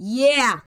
19 RSS-VOX.wav